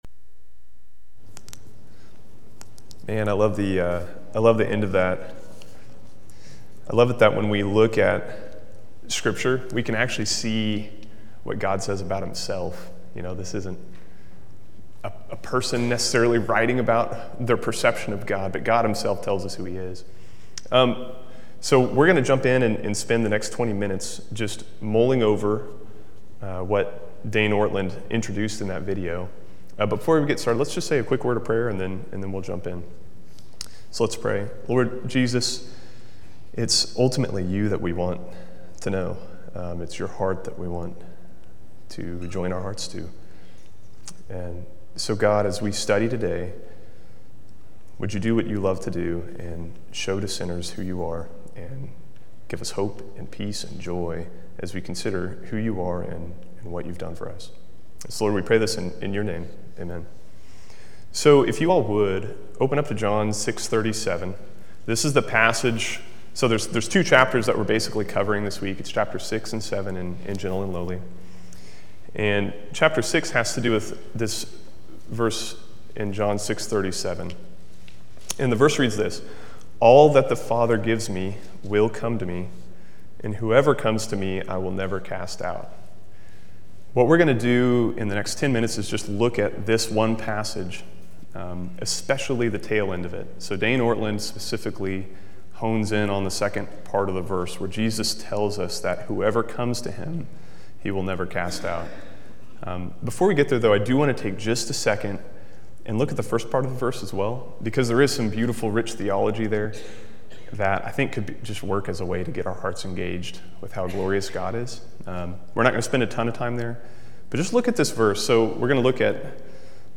We continue working through the book Gentle and Lowly in Adult Sunday School studying chapters 6 and 7 of the book.